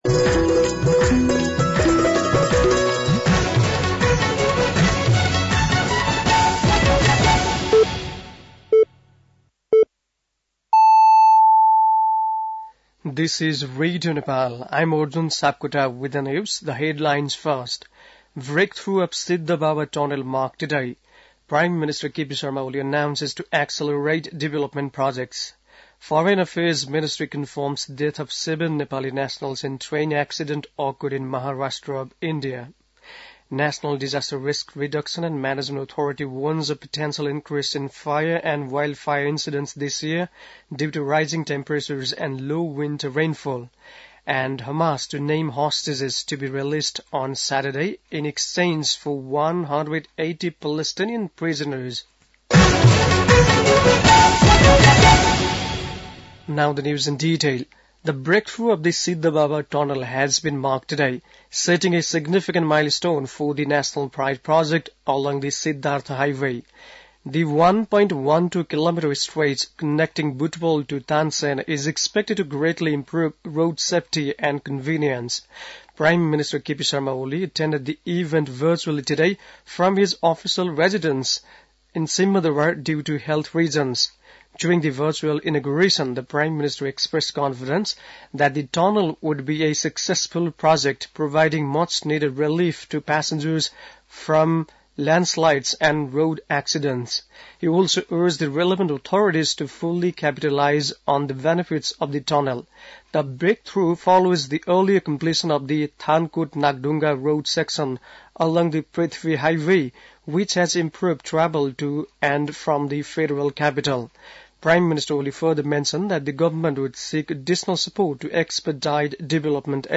बेलुकी ८ बजेको अङ्ग्रेजी समाचार : १२ माघ , २०८१
8-PM-English-NEWS-10-11.mp3